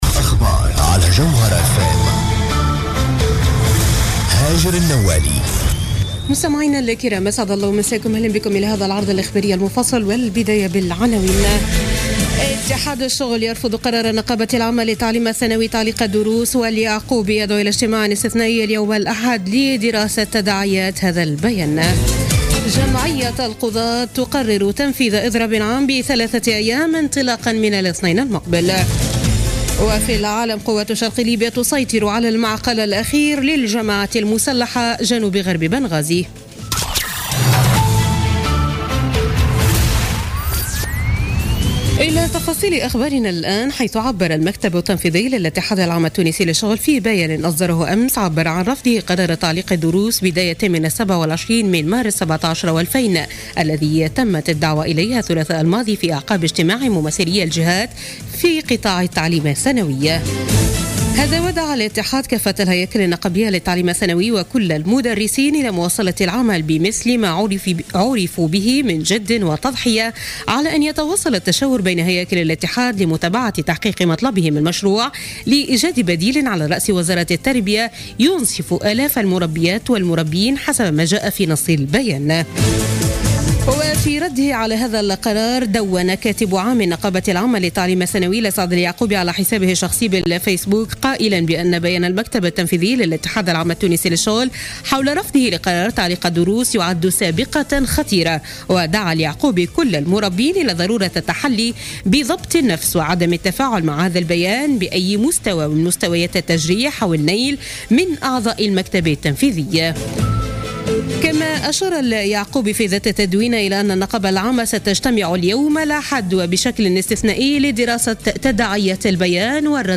نشرة أخبار منتصف الليل ليوم الأحد 19 مارس 2017